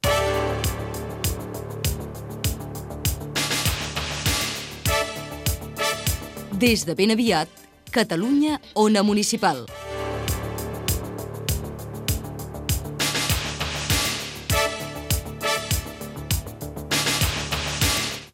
Identificació del programa "Ben aviat" i de l'emissora com Catalunya Ona Municipal
Informatiu